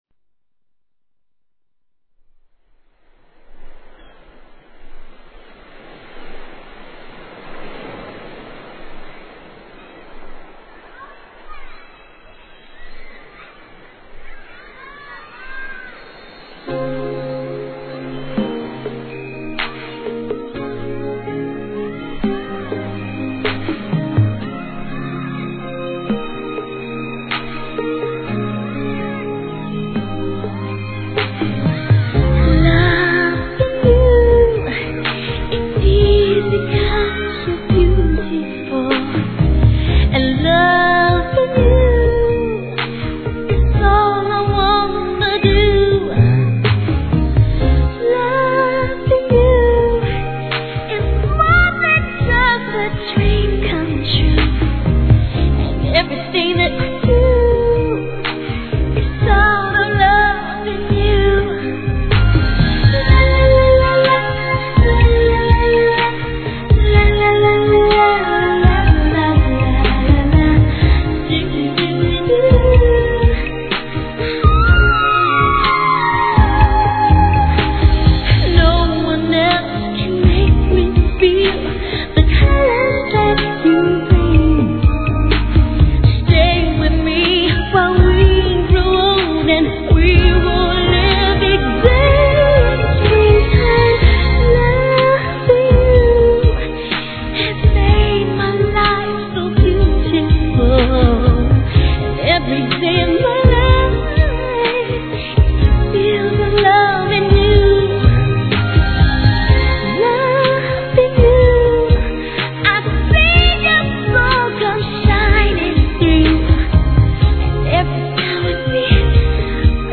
HIP HOP/R&B
あのキュートなヴォイスで「Hah〜〜〜ah~~~♪」にやられます★